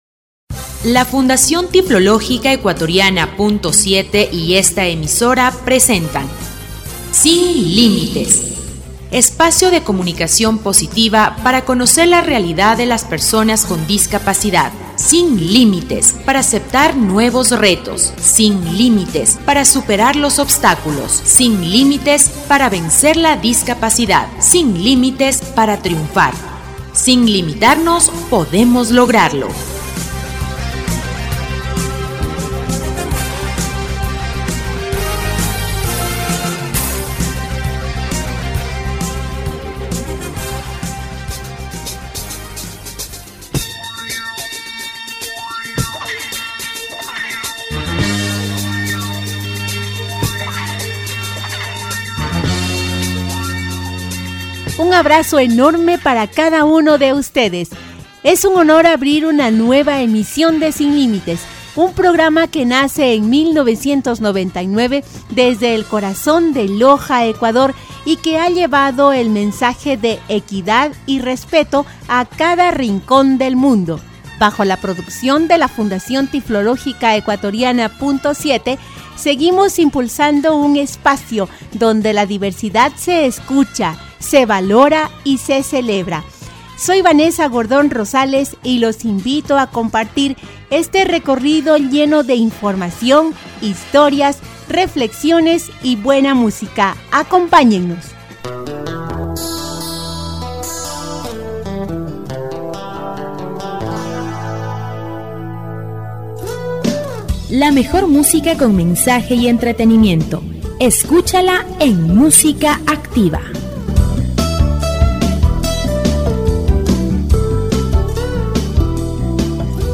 Programa radial «Sin Límites» 1355